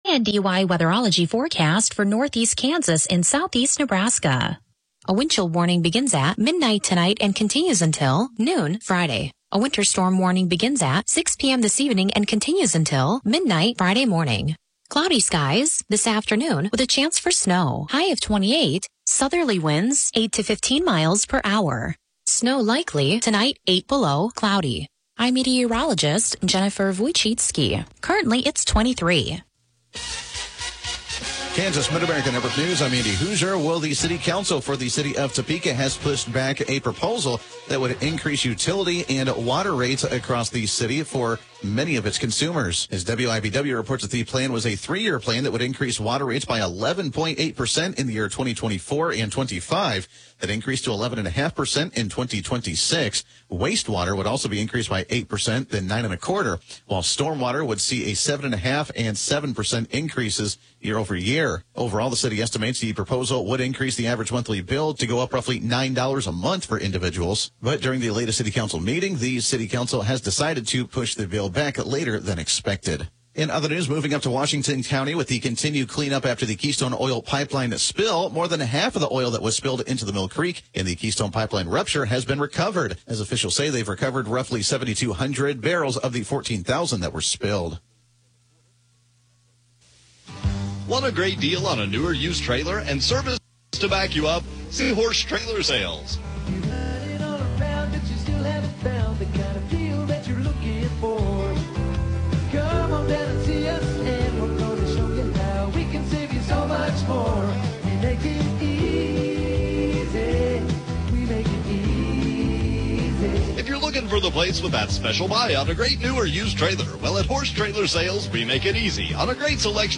Broadcasts are archived daily as originally broadcast on Classic Country AM 1570/FM 94.1 KNDY.